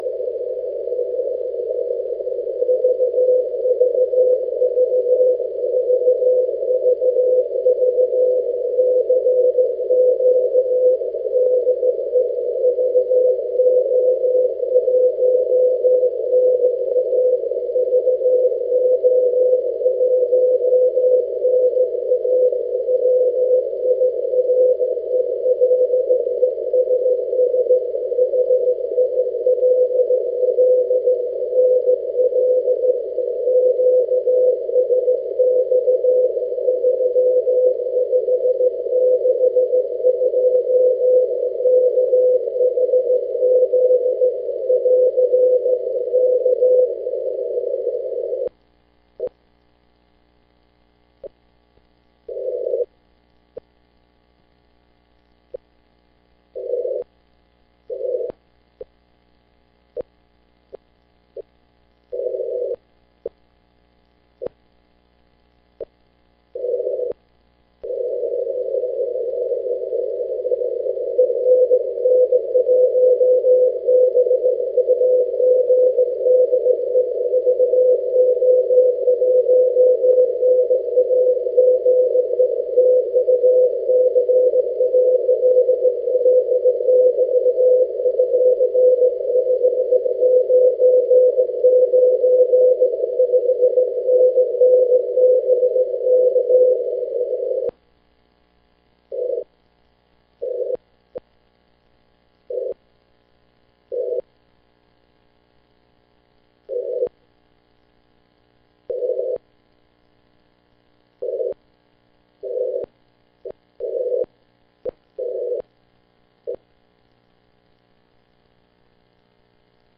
His signal was stronger an hour or two after this QSO.